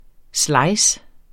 Udtale [ ˈslɑjs ]